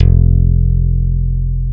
Index of /90_sSampleCDs/Roland LCDP02 Guitar and Bass/BS _Rock Bass/BS _Chapmn Stick